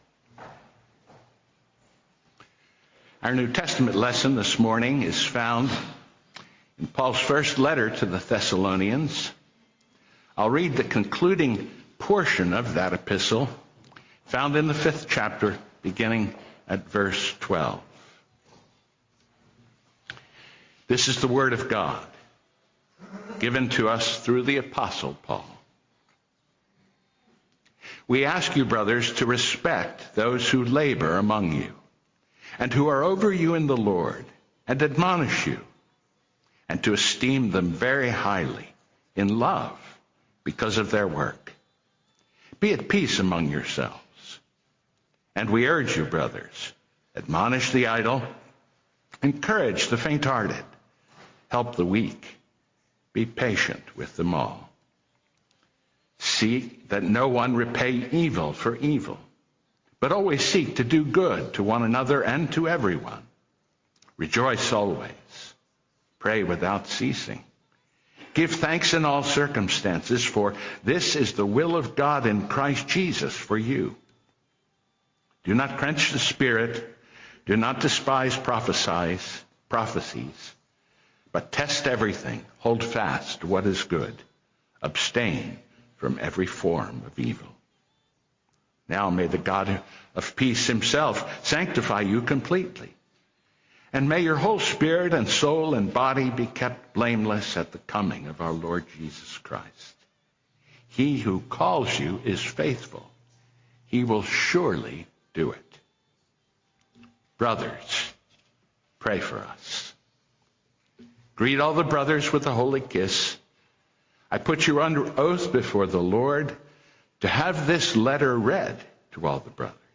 Pray for Ministers of the Gospel, Part 1: Sermon on 1Thessalonians 5:12-28 - New Hope Presbyterian Church